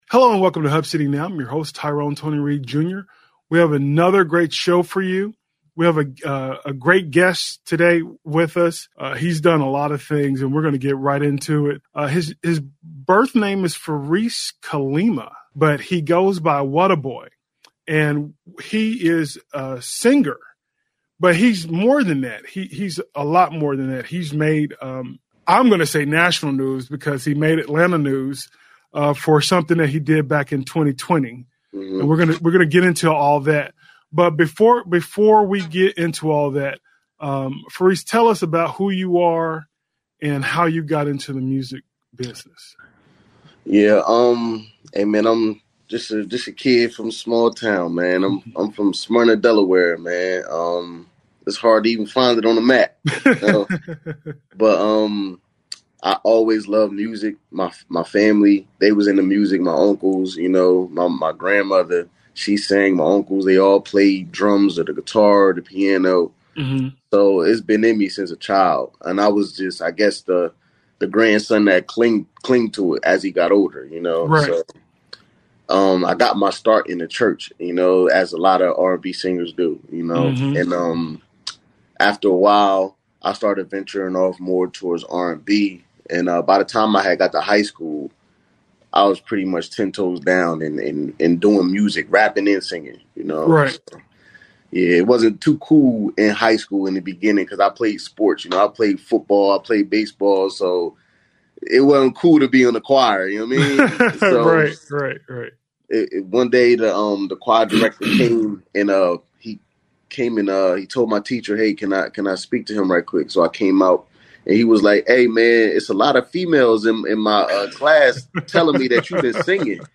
Hub City Now: Interview